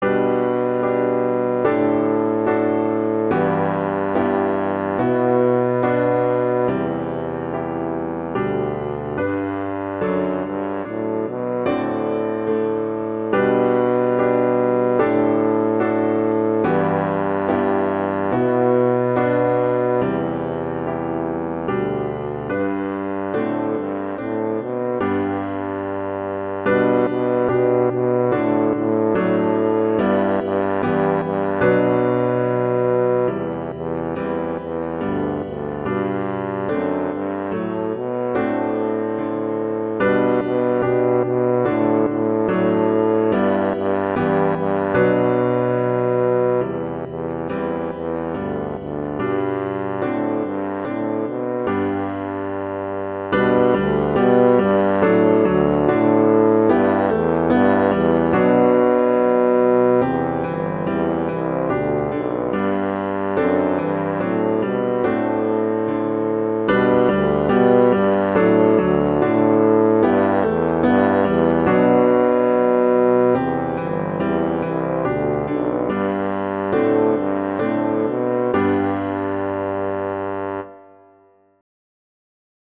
Instrumentation: tuba & piano (or other instruments)
G major
♩=72 BPM